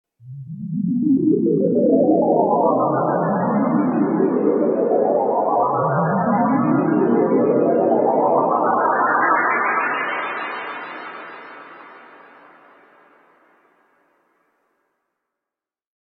Fantasy Transition Sound Effect
Excellent sound effect for a magical transition, dreamy scene, or fantasy-style intro. Features enchanting tones and atmospheric elements, perfect for animations, video games, cinematic reveals, fairy tale sequences, and mystical intros and logos.
Genres: Sound Logo
Fantasy-transition-sound-effect.mp3